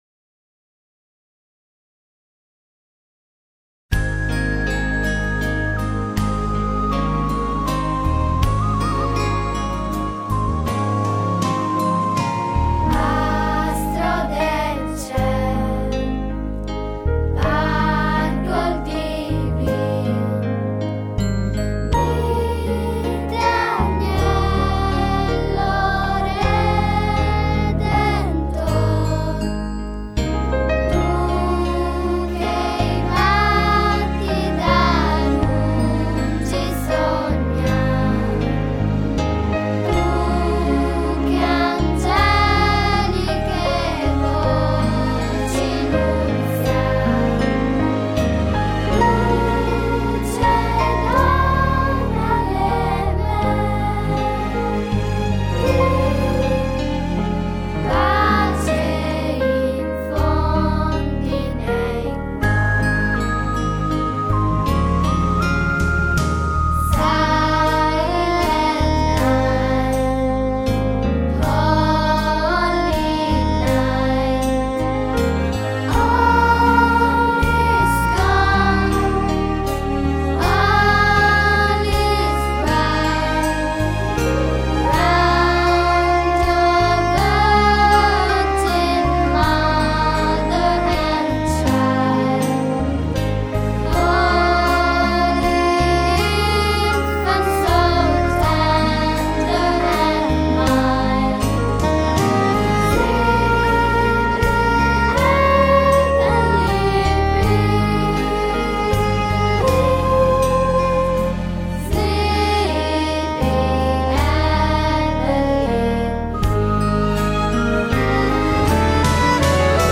di registrare il canto natalizio